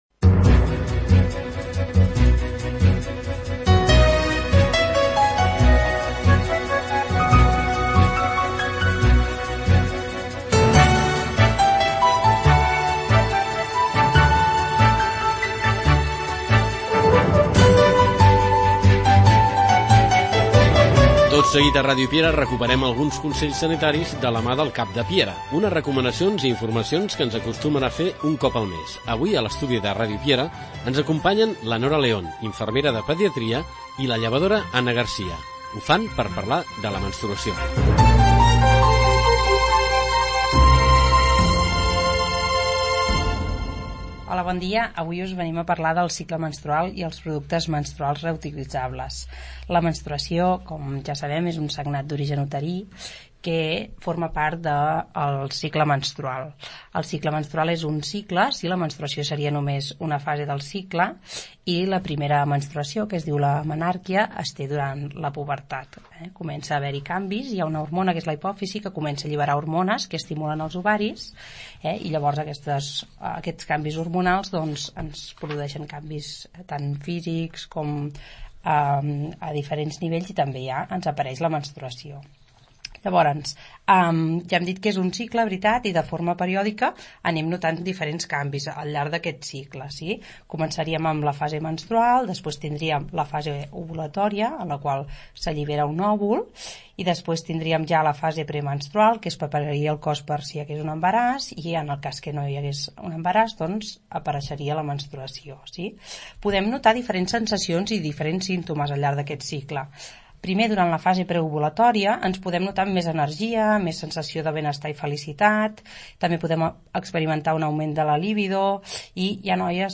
72f4864cbae57e6685e07450cc850059c666b754.mp3 Títol Ràdio Piera Emissora Ràdio Piera Titularitat Pública municipal Descripció Presentació de l'espai, dues professionals del Centre d'Atenció Primària de Piera parlen del cicle menstrual femení.
Divulgació